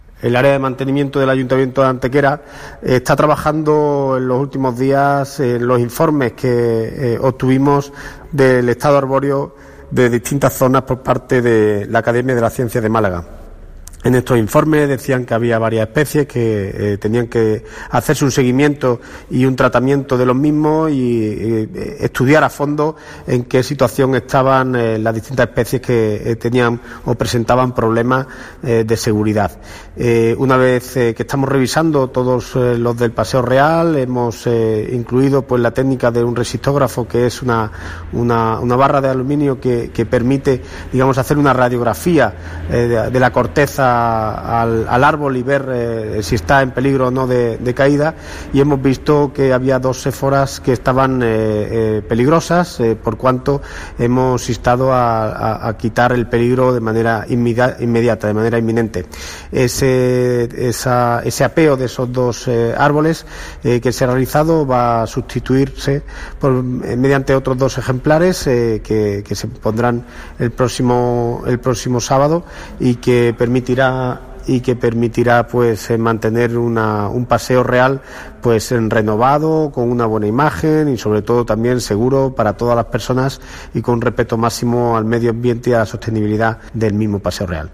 Cortes de voz J. Carmona 600.18 kb Formato: mp3